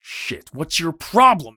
gun_jam_6.ogg